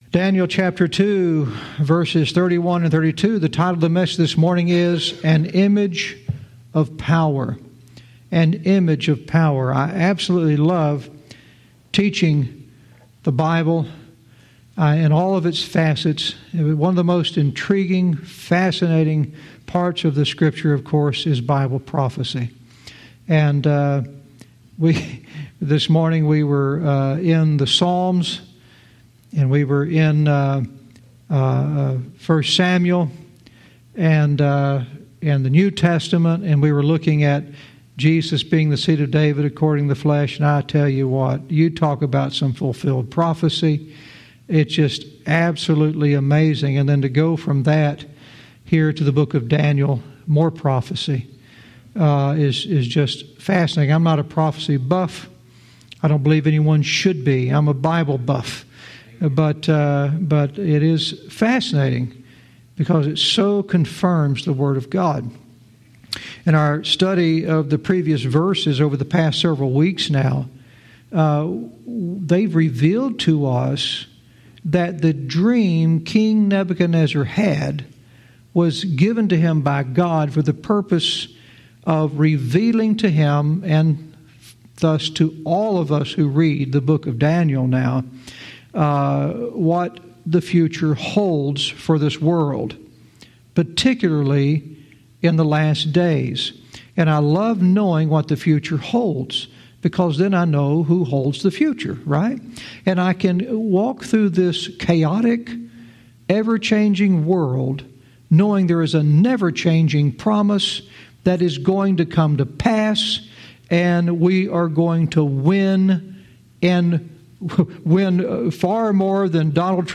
Verse by verse teaching - Daniel 2:31-32 "An Image of Power"